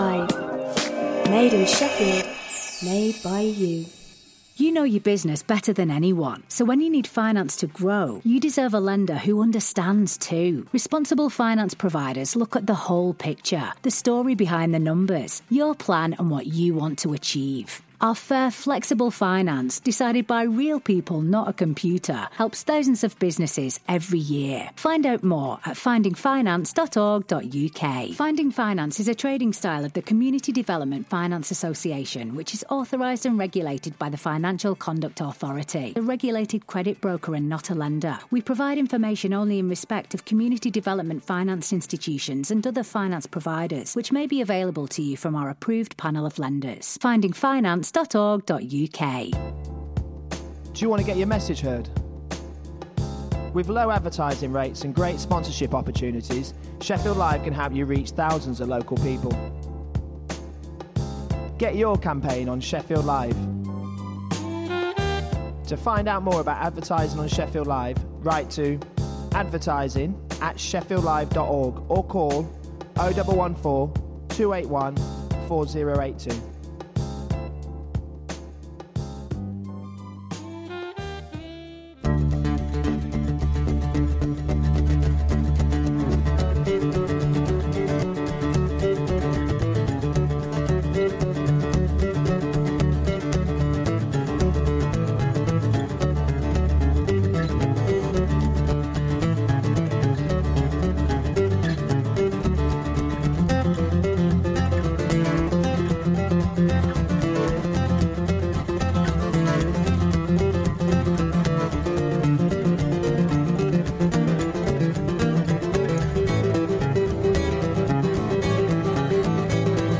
Traditional folk music from the British Isles